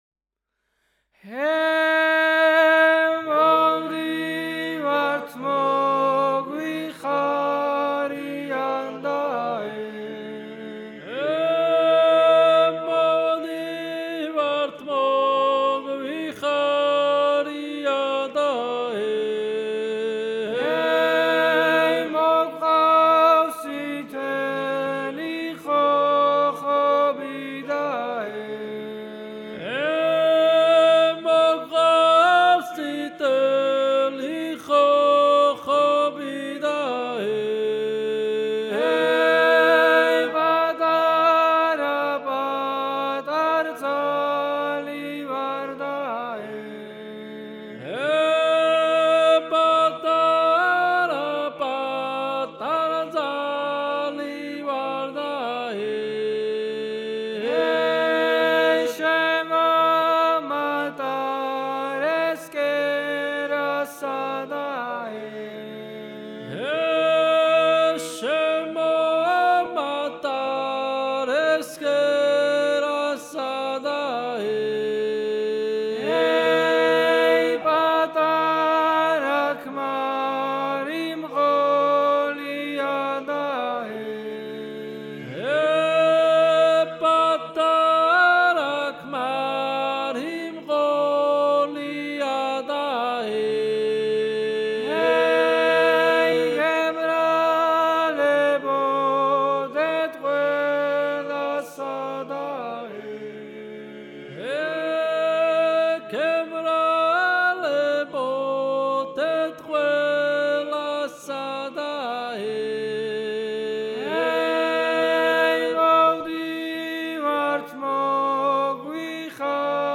სასწავლო ჩანაწერი I ხმ